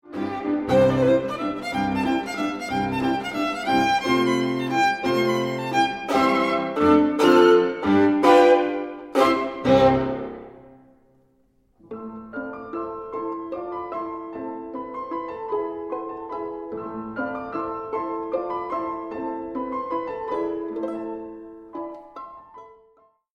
mp3Maier, Amanda, Six Pieces for violin, No. 6, Allegro, ma non troppo, Frisch, schwedisch